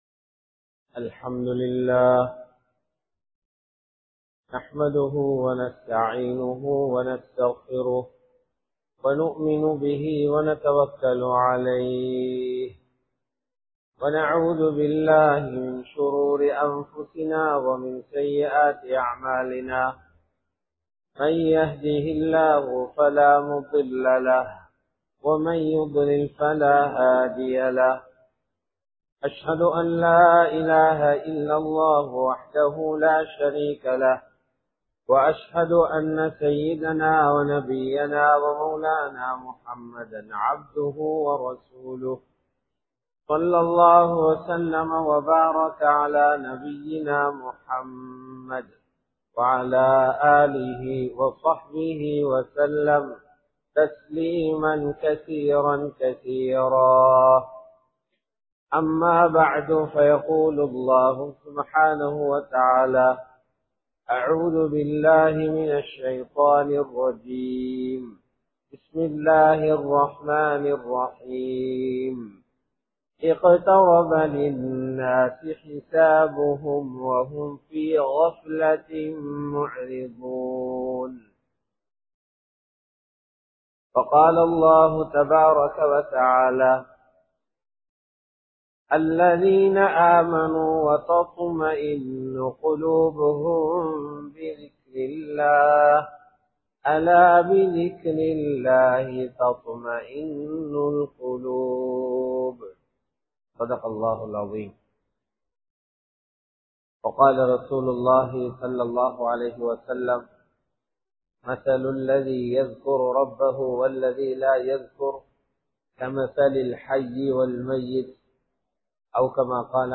ஷஃபான் மாதத்தின் சிறப்புகள் | Audio Bayans | All Ceylon Muslim Youth Community | Addalaichenai